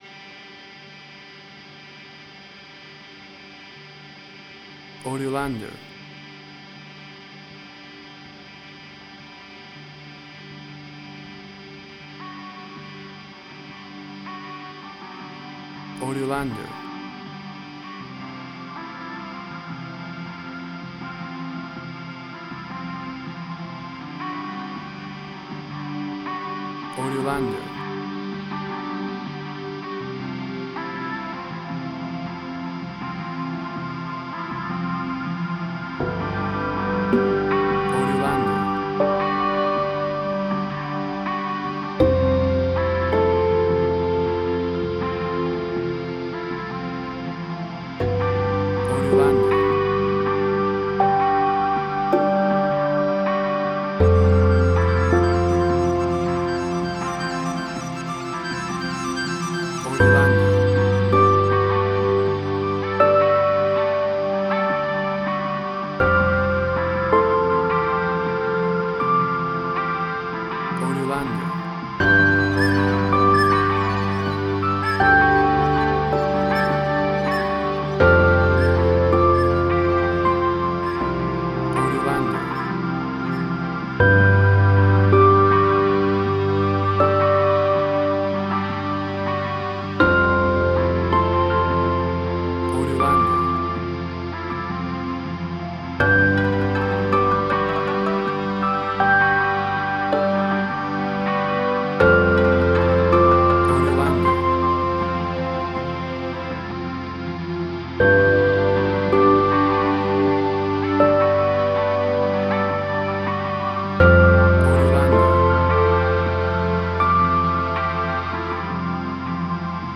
Cinematic Industrial Sci-fi
Tempo (BPM): 80